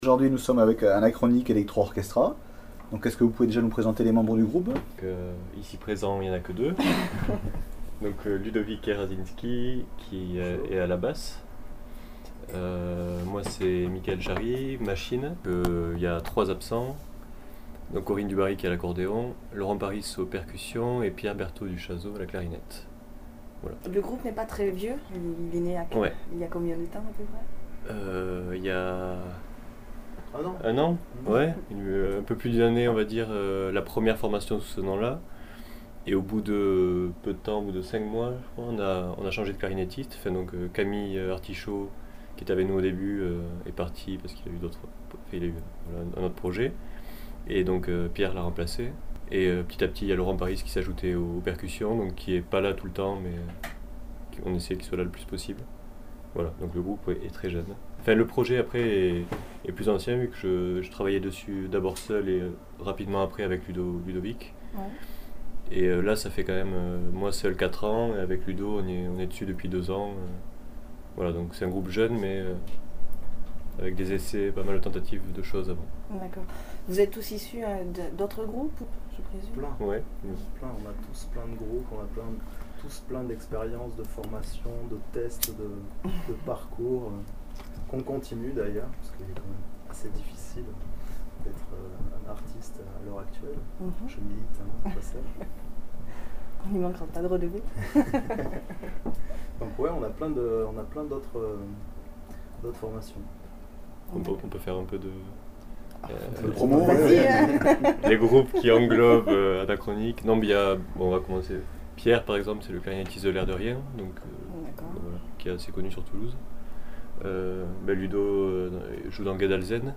Interview réalisée lors de son concert à Auch pour Amnesty International le 8 mars 2008.